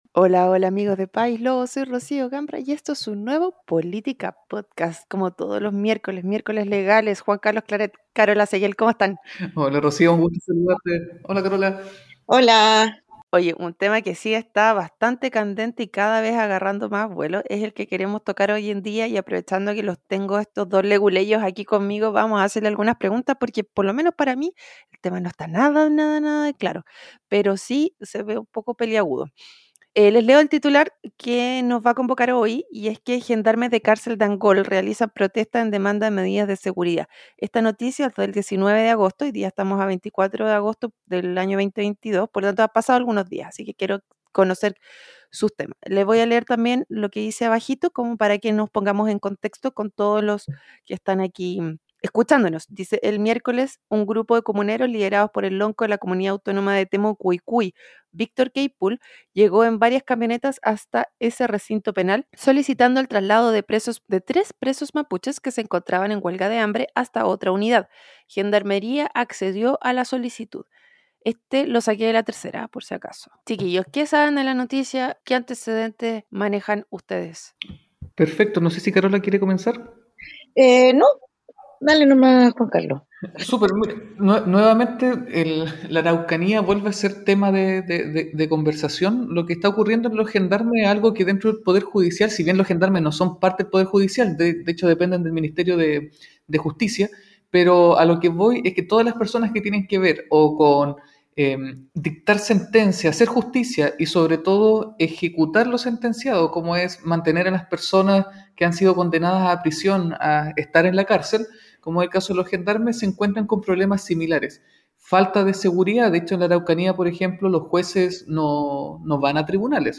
En este Política Podcast una ingeniera
dos abogados